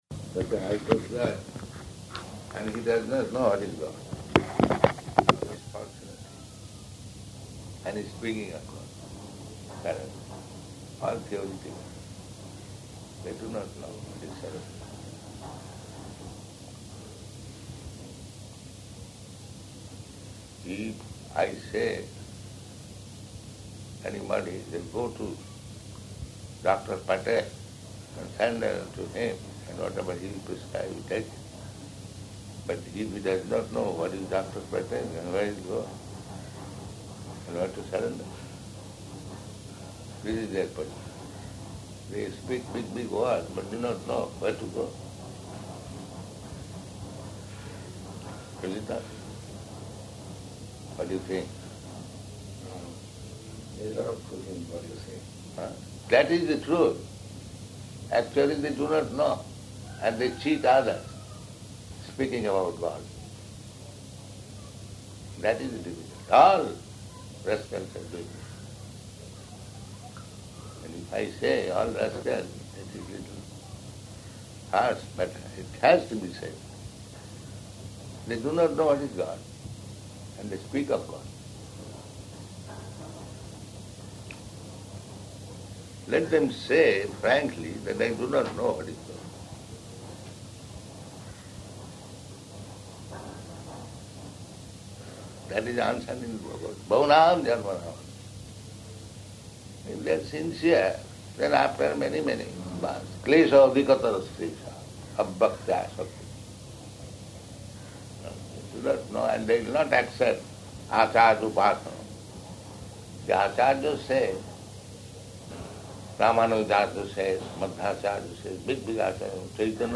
Room Conversation
Location: Bombay